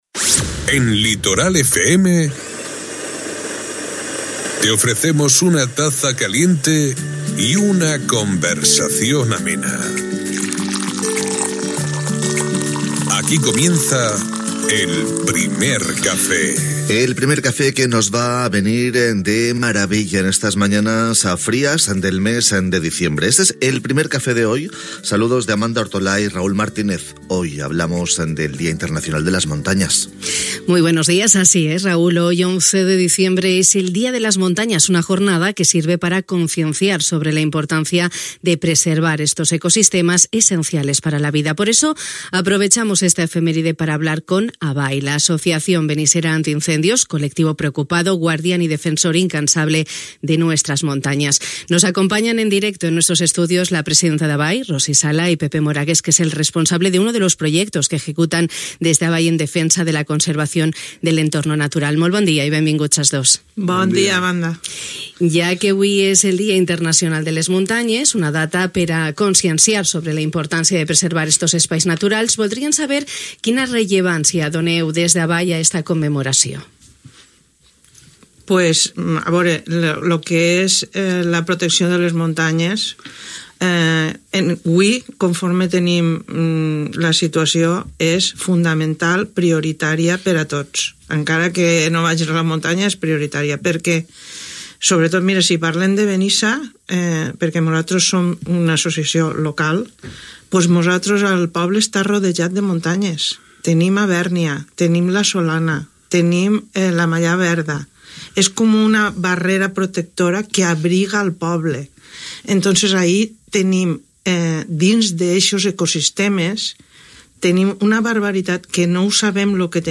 Ràdio Litoral hem aprofitat aquesta efemèride per parlar amb ABAI, l'Associació Benissera Antiincendis.